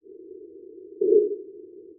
Binaural recordings of broadband noise-bursts played from a loudspeaker in my dining room.
Noise bursts (200 ms) with 10 ms on/off ramps (Hanning) were played from a single loudspeaker in my dining room well above average ambient noise levels (~36 dB).